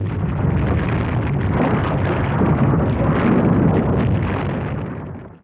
SFX event_earthquake.wav